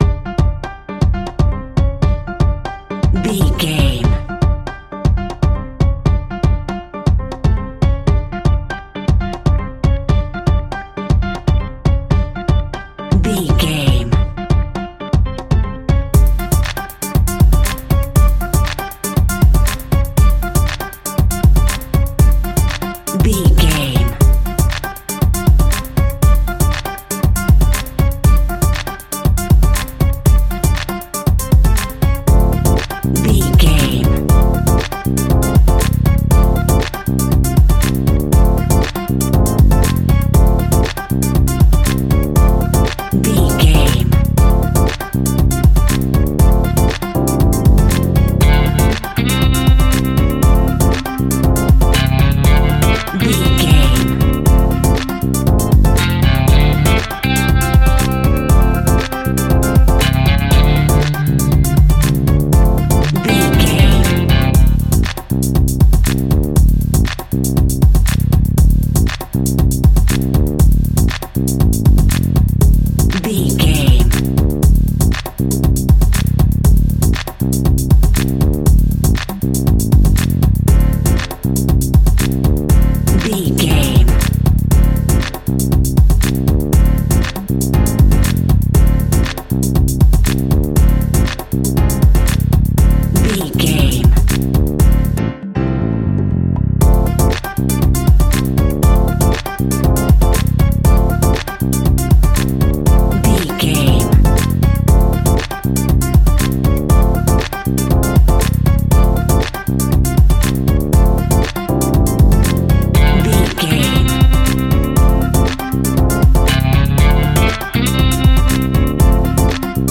Aeolian/Minor
WHAT’S THE TEMPO OF THE CLIP?
drums
bass guitar
percussion
brass
saxophone
trumpet
fender rhodes
clavinet